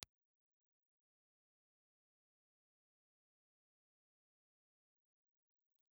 Impulse Response file of STC 4033A microphone in position R
STC4033_Ribbon_IR.wav
• R = Ribbon (figure-8, ribbon element only)